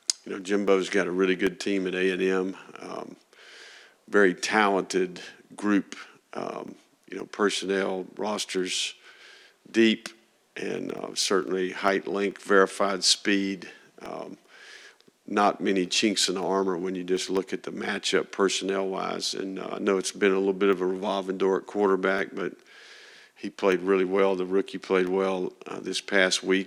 Gator football head coach Billy Napier talked the upcoming matchup against Texas A&M and the dismissal of linebacker Brenton Cox Jr. in his weekly press conference on Monday.